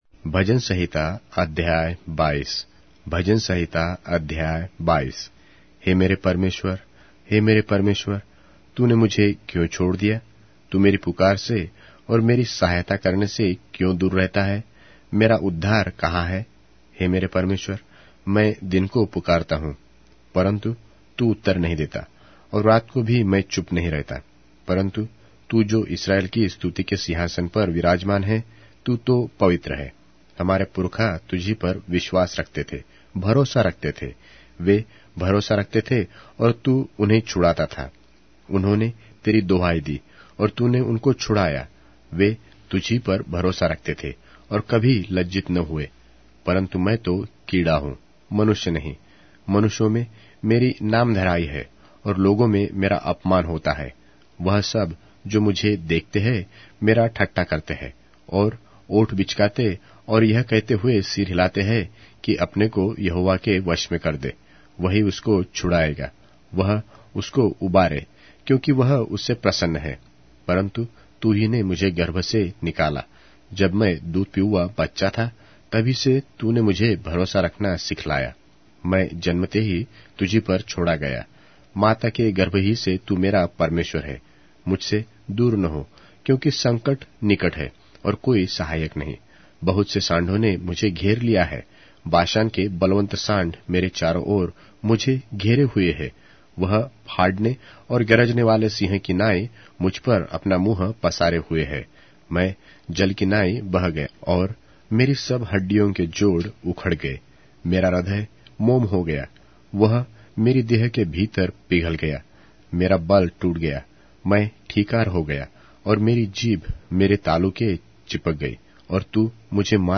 Hindi Audio Bible - Psalms 55 in Ervta bible version